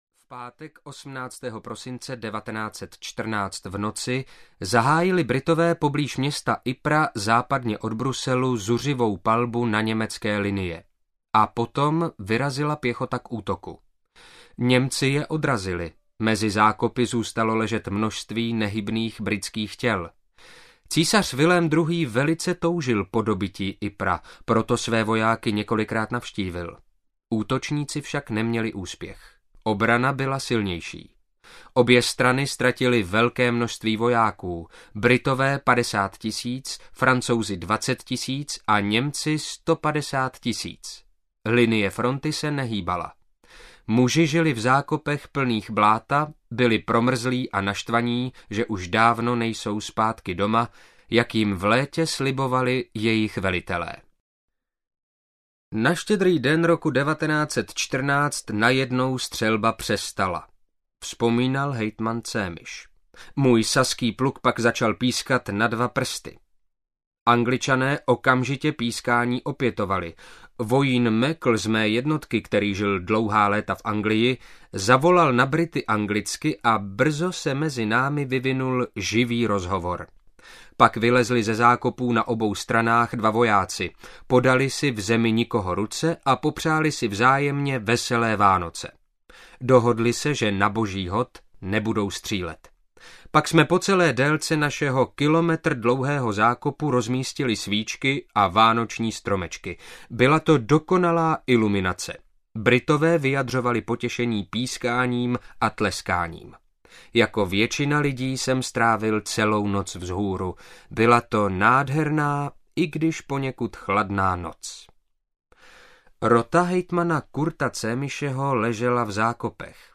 Doteky dějin audiokniha
Ukázka z knihy